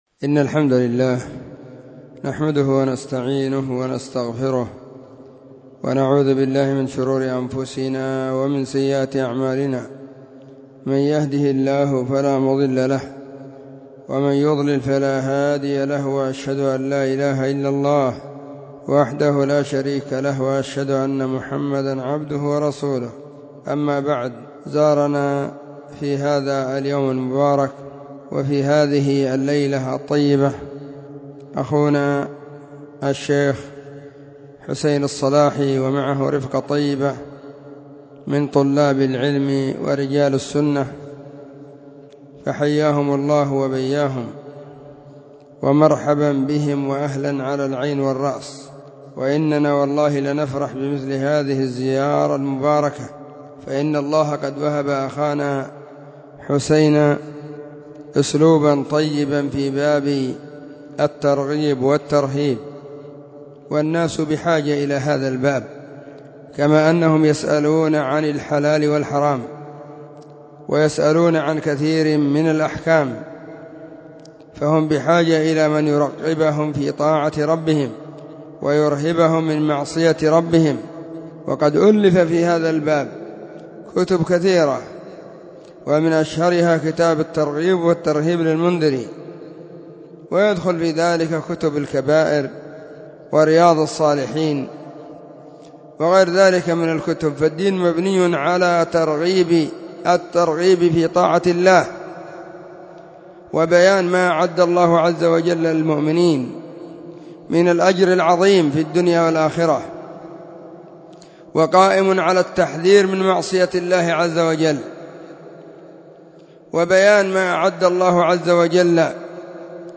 📢 مسجد الصحابة – بالغيضة – المهرة، اليمن حرسها الله.
الثلاثاء 24 جمادى الآخرة 1441 هــــ | كلمــــات | شارك بتعليقك